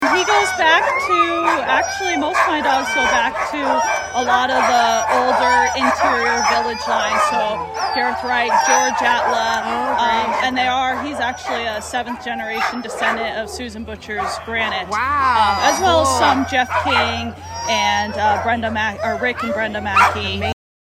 Current Location: Downtown Anchorage at the Ceremonial Iditarod Start